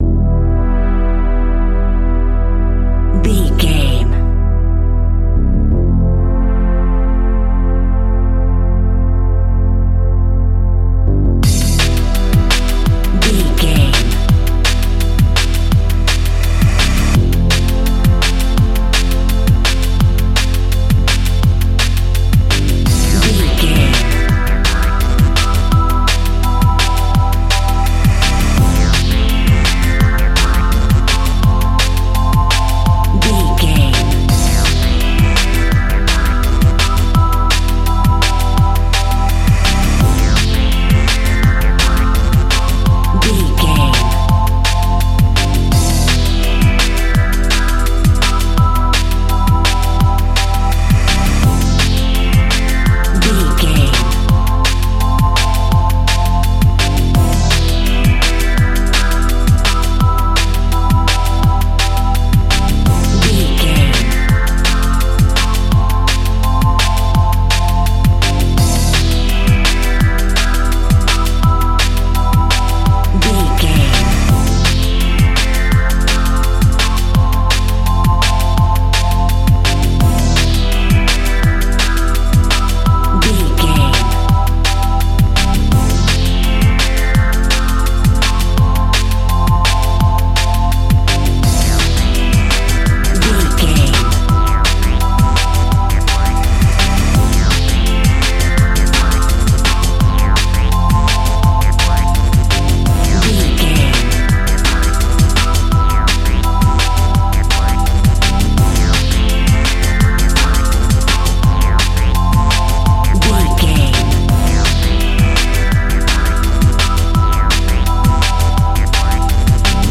Aeolian/Minor
Fast
futuristic
frantic
energetic
driving
synthesiser
electric piano
drum machine
electronic
synth bass
synth lead
synth pad
robotic